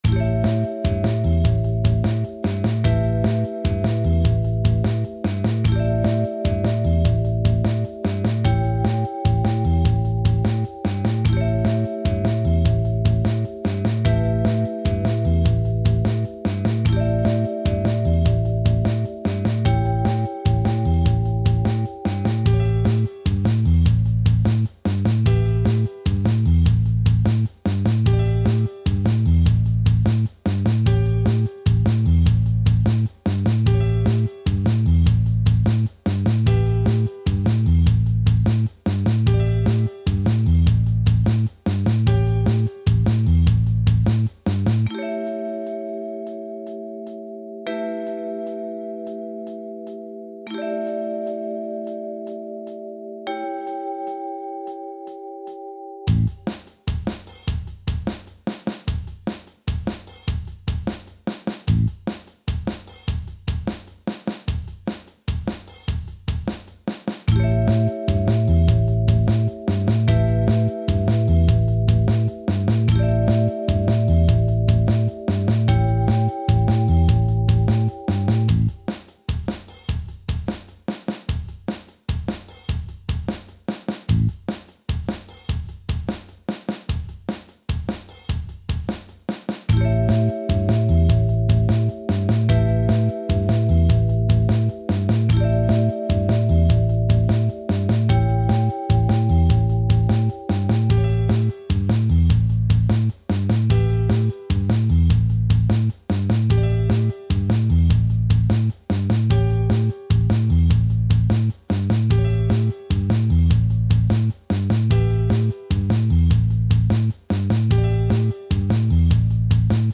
Playbacks pour improviser - Théorie
MODES MAJEURS
- Mixolydien GuitarPro6
Mixolydien7temps.mp3